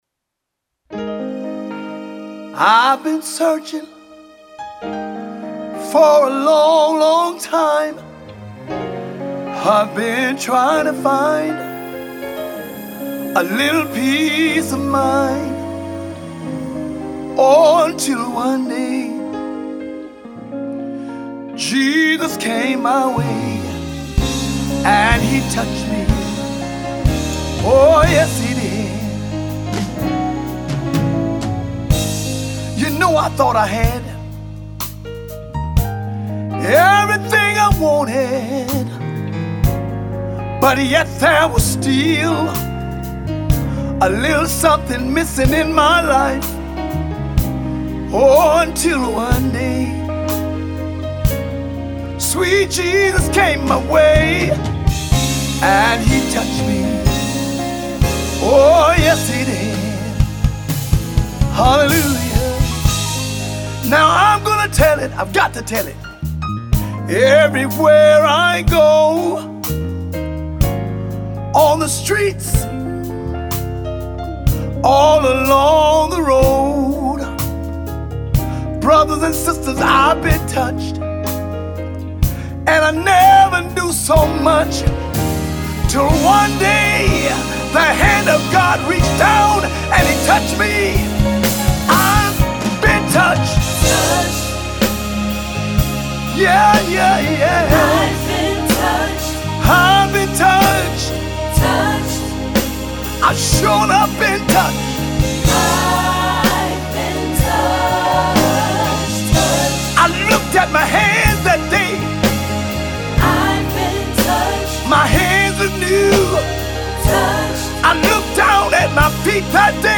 Great Gospel Music